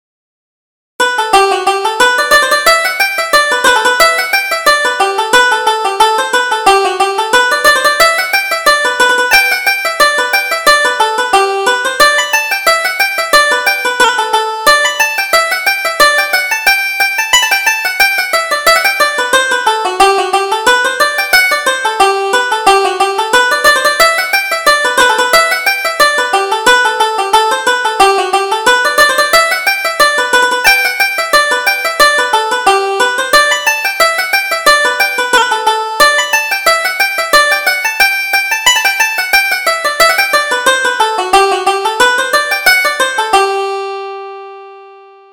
Reel: The Harvest Field